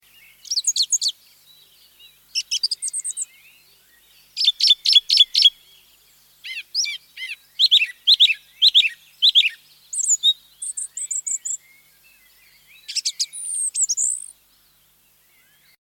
Birdsong 6